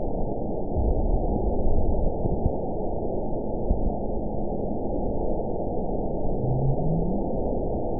event 917174 date 03/22/23 time 23:46:45 GMT (2 years, 1 month ago) score 9.66 location TSS-AB04 detected by nrw target species NRW annotations +NRW Spectrogram: Frequency (kHz) vs. Time (s) audio not available .wav